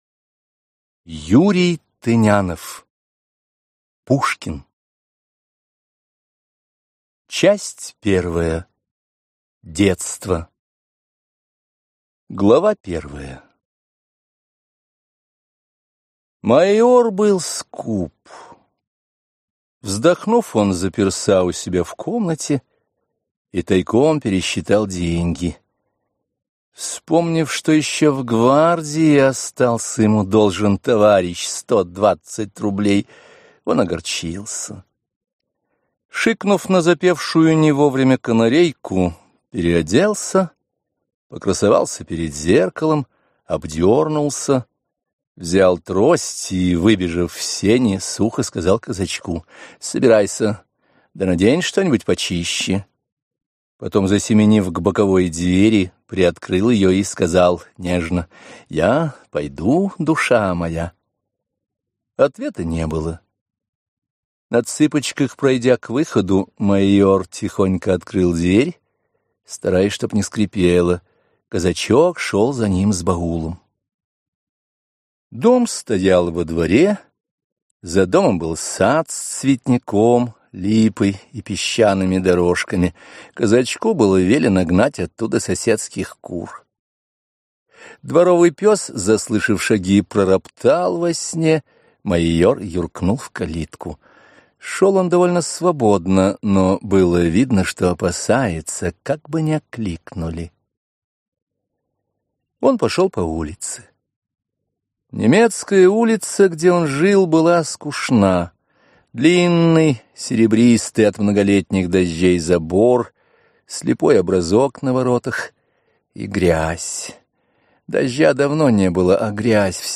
Аудиокнига Пушкин | Библиотека аудиокниг